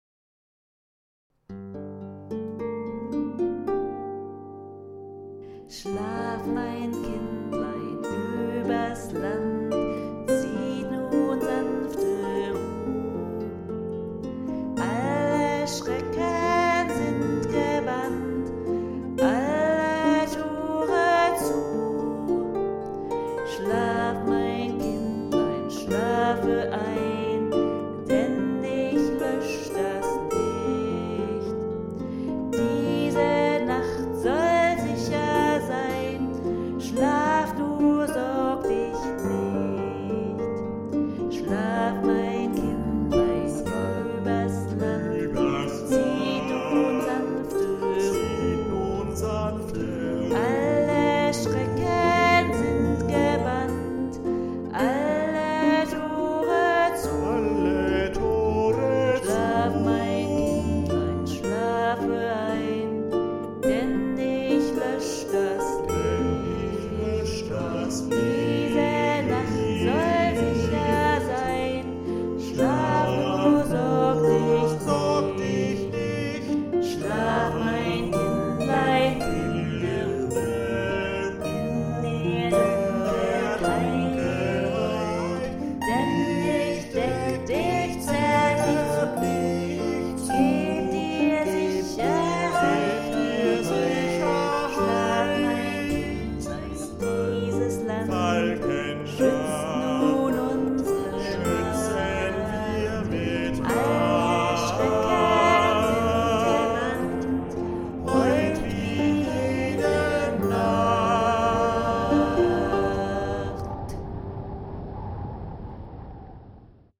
Tenor
Bariton